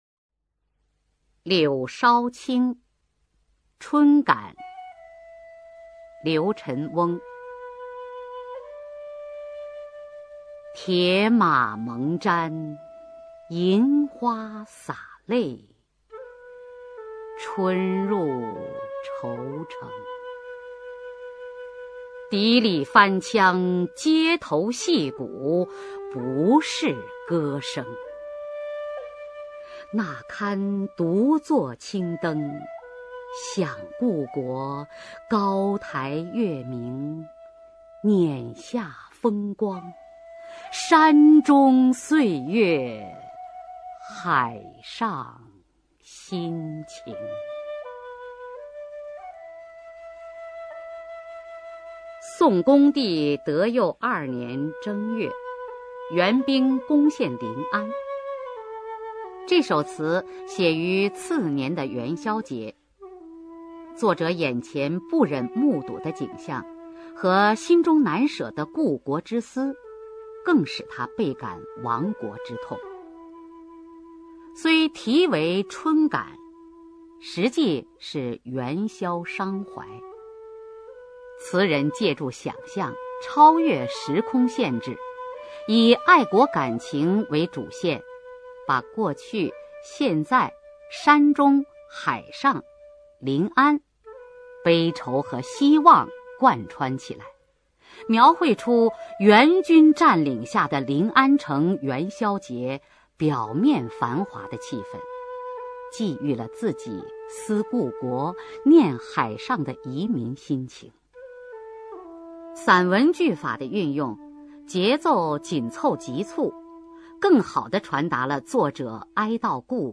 [宋代诗词朗诵]刘辰翁-柳梢青·春感 古诗词诵读